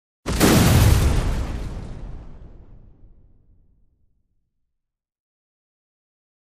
Explosion Heavy Glass Destruction Type 2 Version 2 - Short